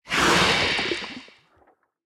Minecraft Version Minecraft Version 1.21.5 Latest Release | Latest Snapshot 1.21.5 / assets / minecraft / sounds / entity / squid / squirt2.ogg Compare With Compare With Latest Release | Latest Snapshot
squirt2.ogg